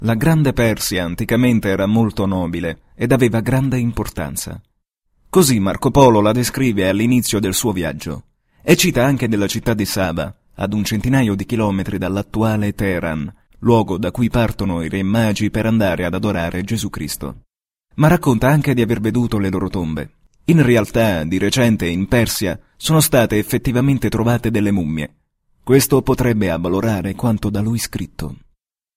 Sprechprobe: eLearning (Muttersprache):
Warm italian voice for documntary, e -learning, audioguide, audiobook etc.